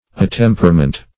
Attemperment \At*tem"per*ment\, n.
attemperment.mp3